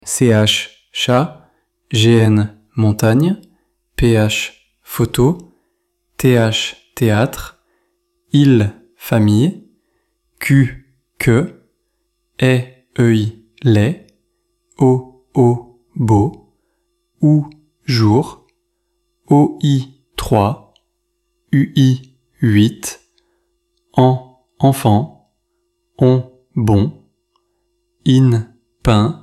When n or m follows a vowel, the sound becomes nasal — air passes through the nose.
You can listen in this audio how these are pronounced :
ElevenLabs_Text_to_Speech_audio-1.mp3